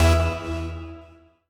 timer-running-out.ogg